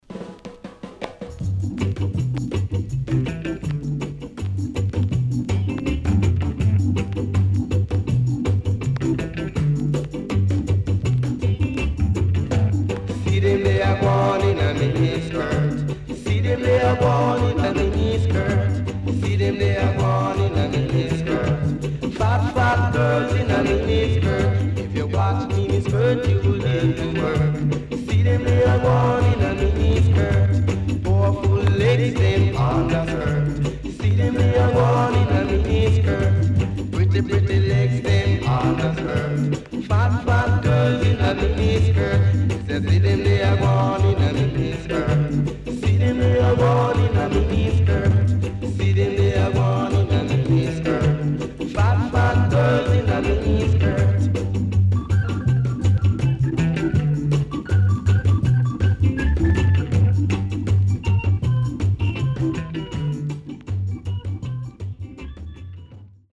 Rare Coupling.W-Side Good Early Reggae Vocal
SIDE A:うすいこまかい傷ありますがノイズあまり目立ちません。